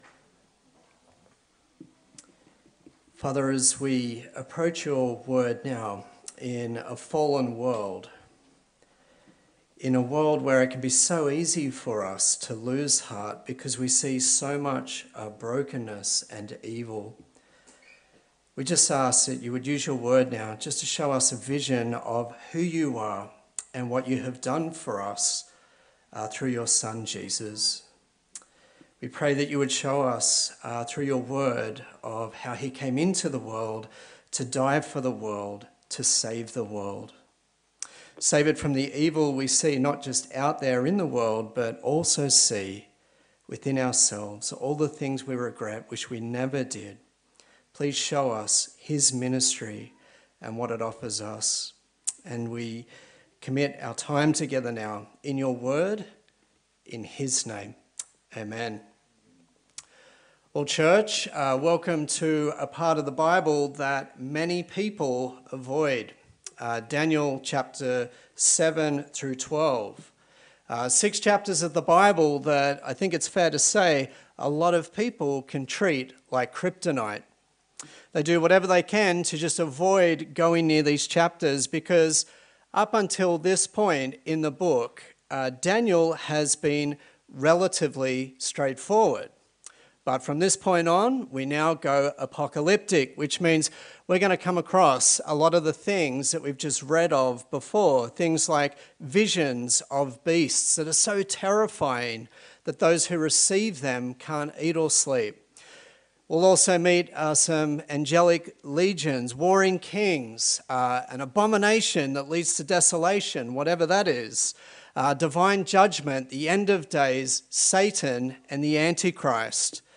A sermon in the series on the book of Daniel
Sunday Service